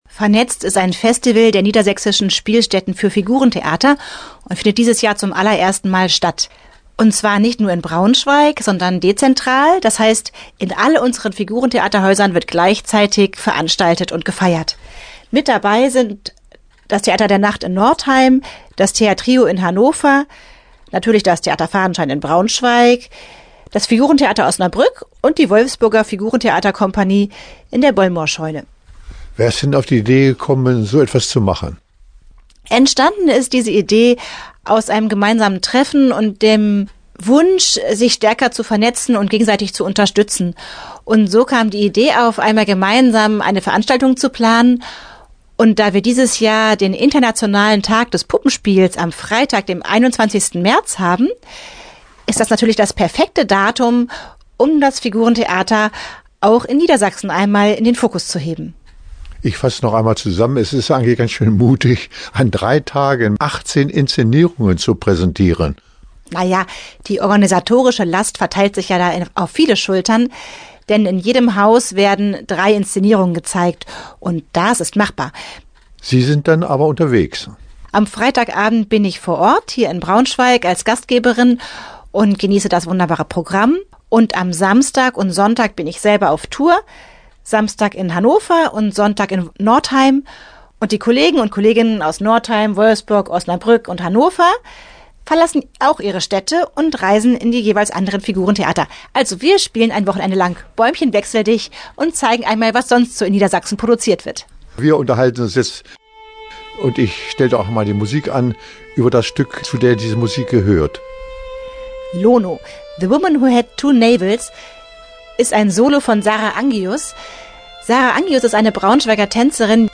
Interview-Fadenschein-Vernetzt.mp3